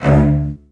CELLOS.EN2-R.wav